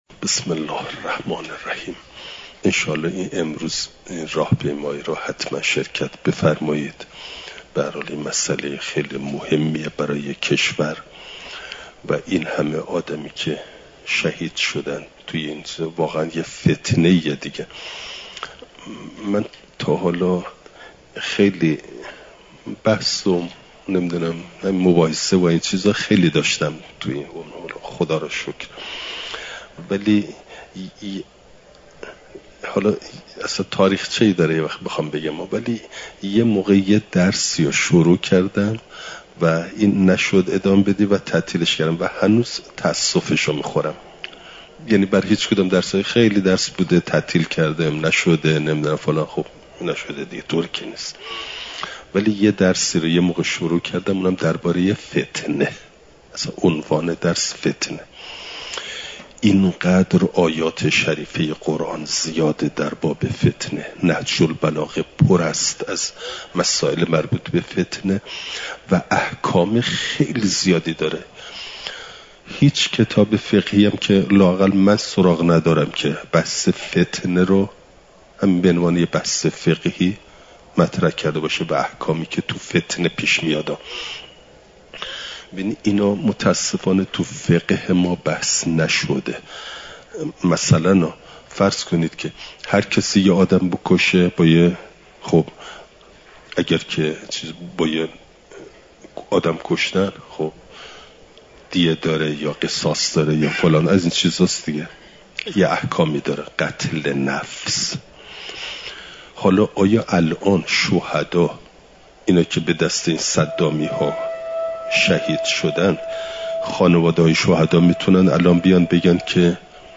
دوشنبه ۲۲دیماه ۱۴۰۴، حرم مطهر حضرت معصومه سلام ﷲ علیها